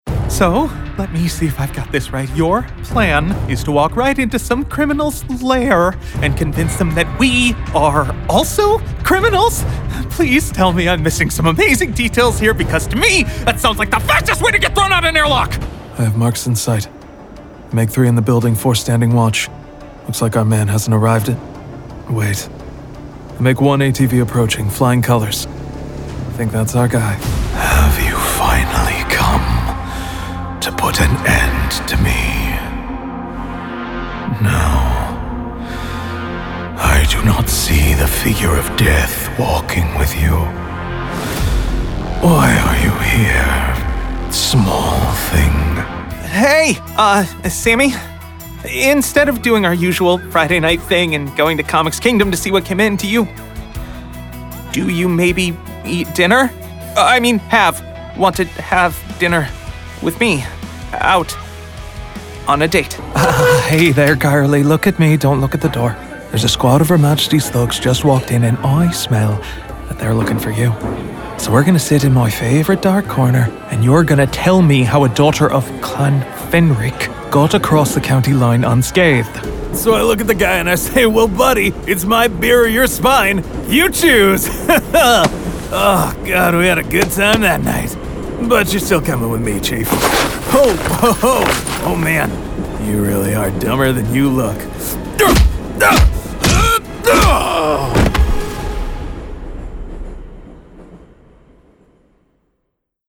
Demos and Reels
Character Demo